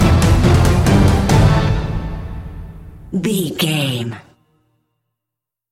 Epic / Action
Fast paced
In-crescendo
Aeolian/Minor
horns
percussion
electric guitar